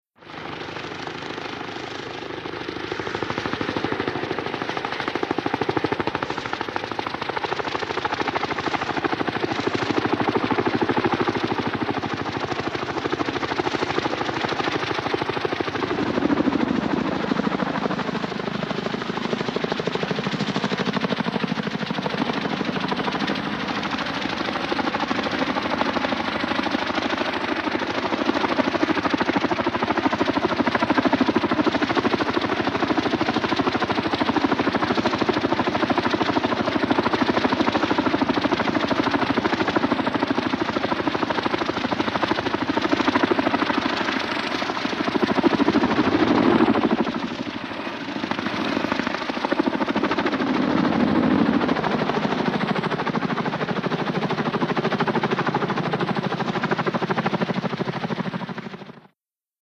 HELICOPTER BELL UH-1 IROQUOIS: EXT: Hovering with good rotor thwops.